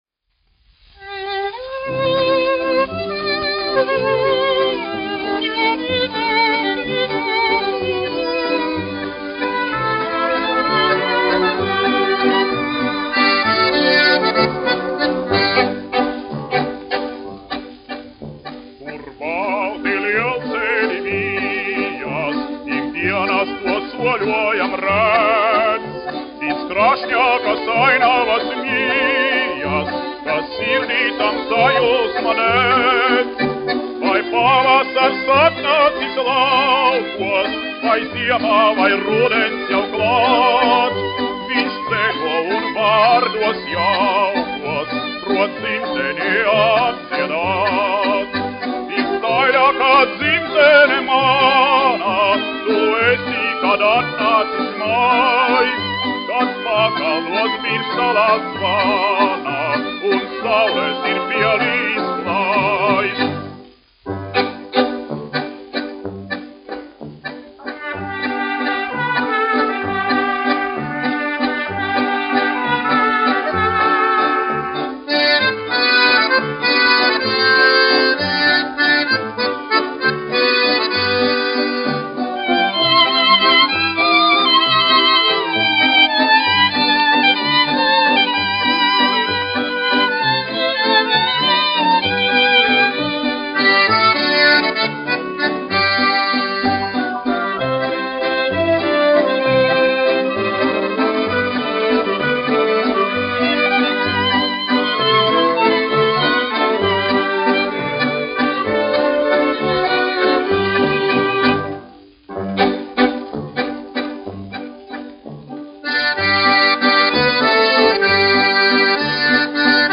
1 skpl. : analogs, 78 apgr/min, mono ; 25 cm
Populārā mūzika
Skaņuplate